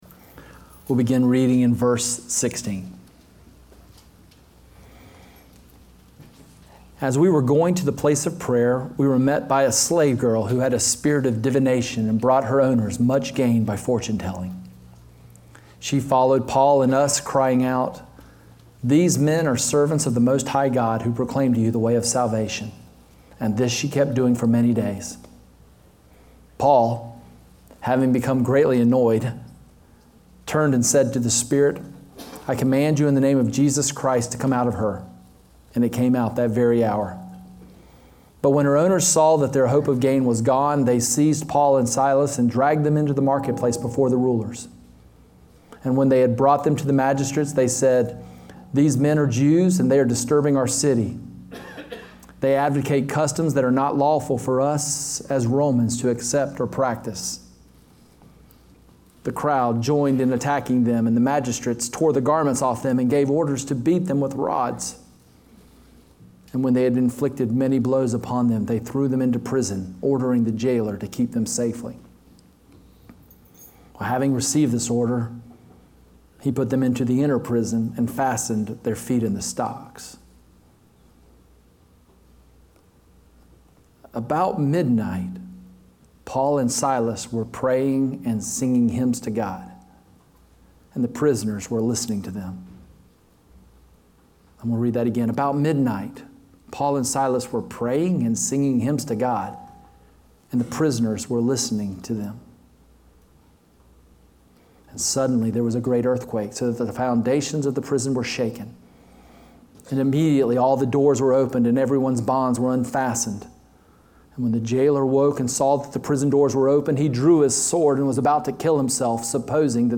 Horizon Church Sermon Audio We too can sing!